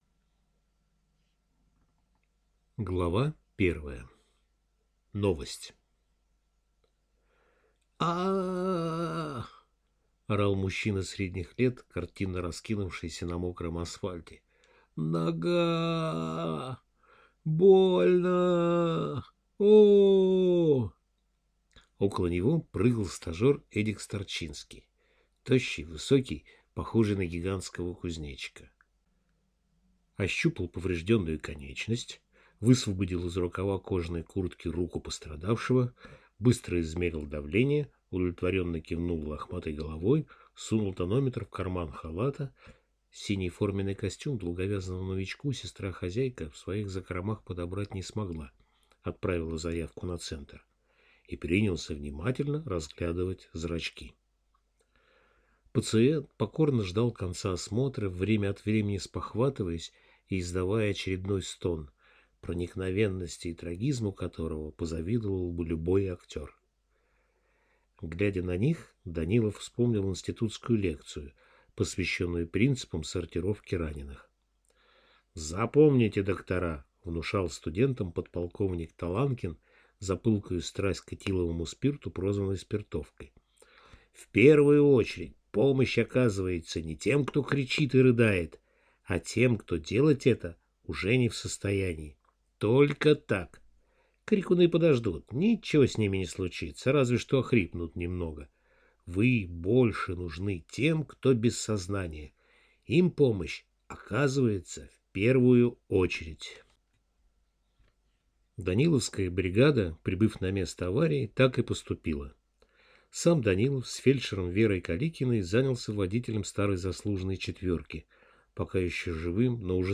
Аудиокнига Байки «скорой помощи» - купить, скачать и слушать онлайн | КнигоПоиск